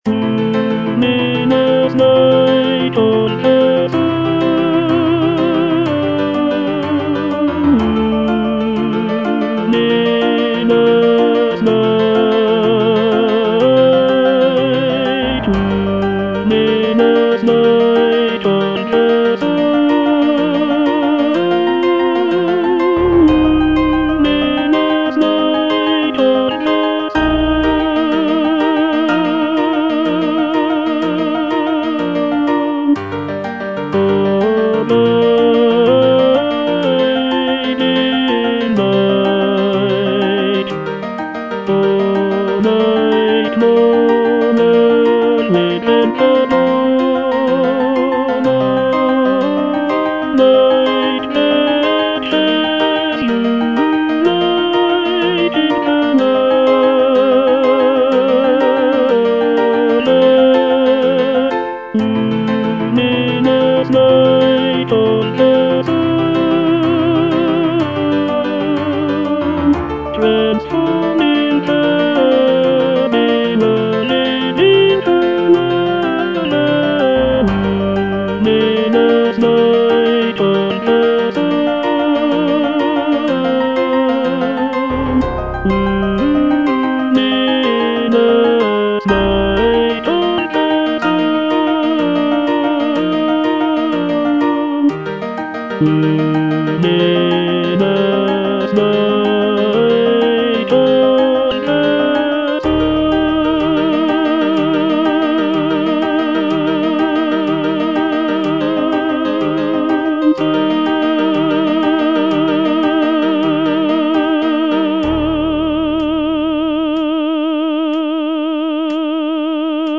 (tenor I) (Voice with metronome) Ads stop
choral work